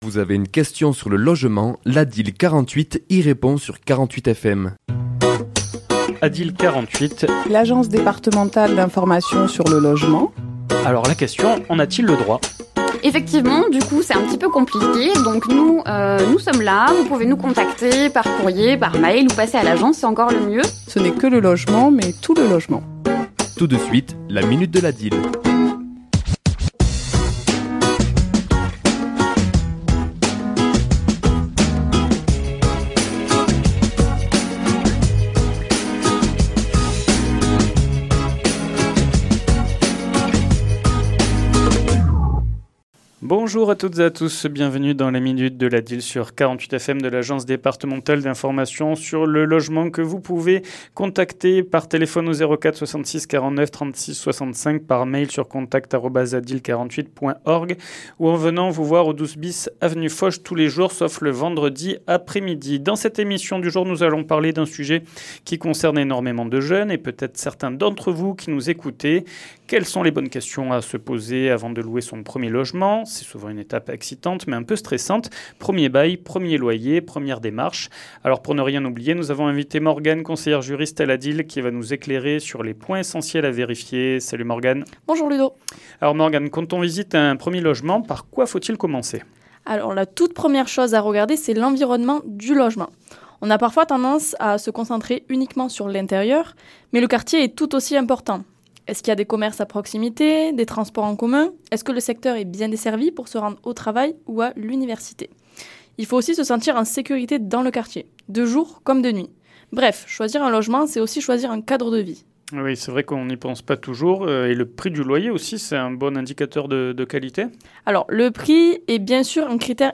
Chronique diffusée le mardi 11 Novembre à 11h et 17h10